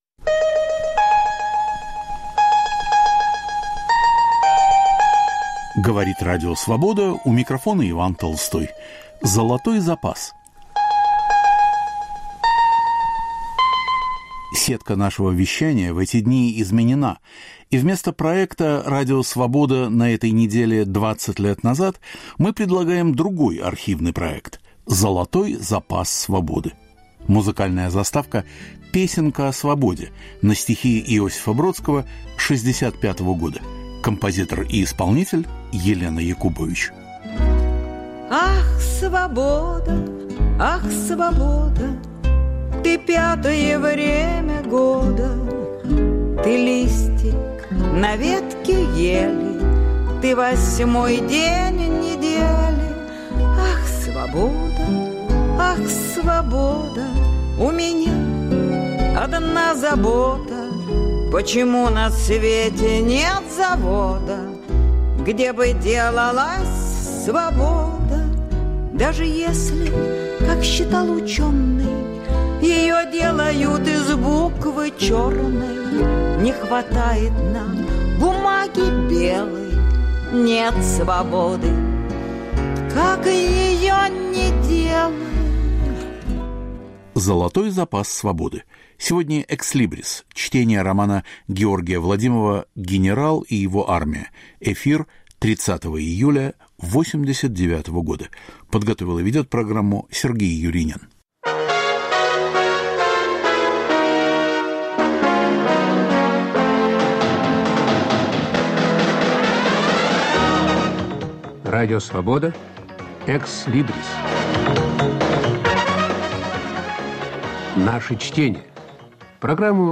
Чтение романа Георгия Владимова "Генерал и его армия". Читает Юлиан Панич в присутствии автора, разъясняющего особенности сюжета.